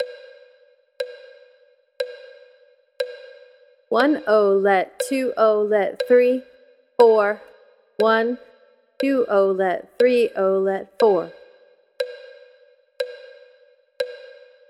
In the first example, the musician uses a variation on the word “triplet” by saying “1-o-let” for each of the triplet notes.
While both methods are being introduced here, for simplicity’s sake, the examples that follow will use the “1-o-let” method to help distinguish a triplet from a 16th note pattern (which you’ll remember is counted “1-ee-and-ah”).
Triplet Exercise 1